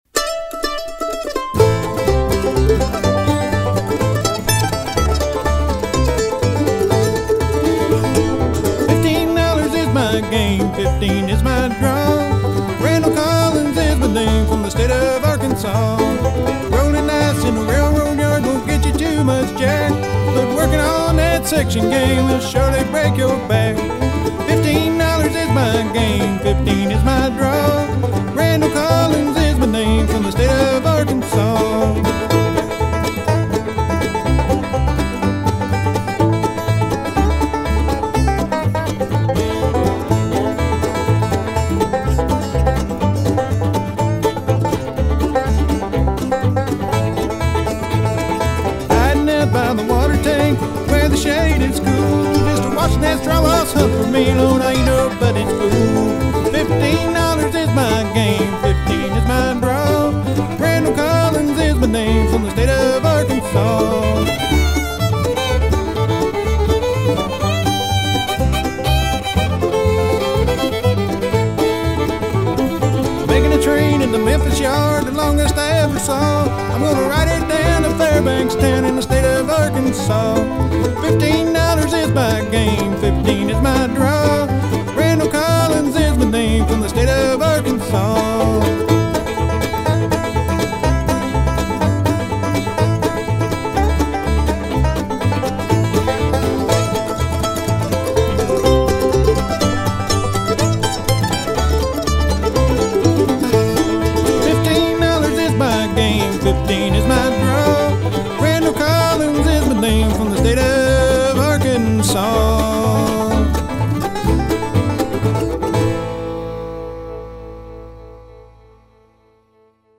Where's a good drawing line for acoustic bluegrass?